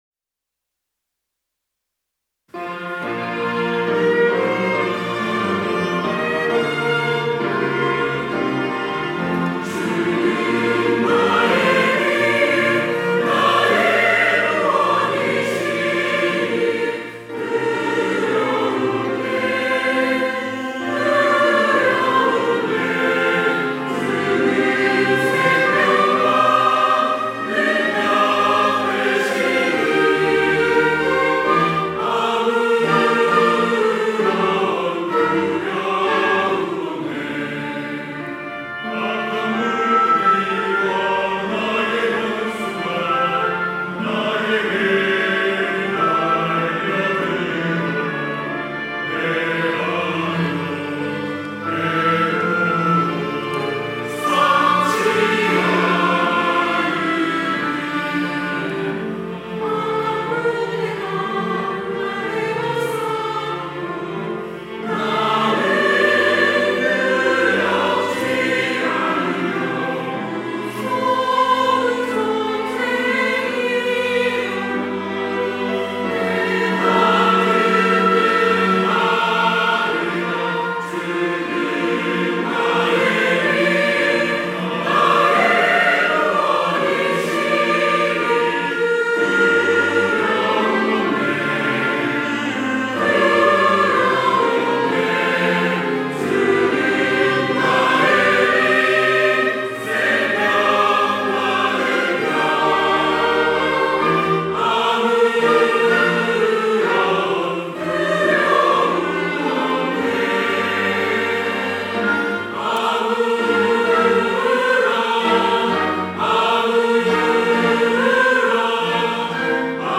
호산나(주일3부) - 주는 나의 빛
찬양대